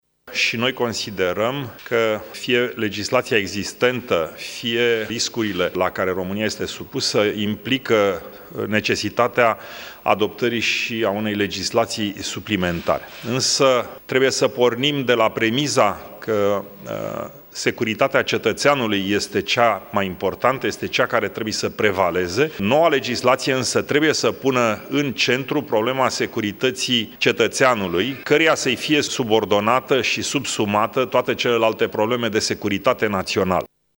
La încheierea consultărilor, președintele ALDE, Călin Popescu Tăriceanu, a declarant că legislația existentă, dar și riscurile la care România e supusă implică adoptarea unei legislații suplimentare, dar securitatea cetățeanului trebuie să prevaleze: